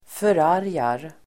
Uttal: [för'ar:jar]